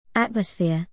atmosphereは「大気」「空気」「雰囲気」という意味で、「アトゥモスフィア [ˈætməsfìɚ]」のように語頭を強く発音します。
（▼ atmosphereの発音）